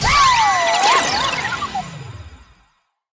Block Exit Sound Effect
block-exit.mp3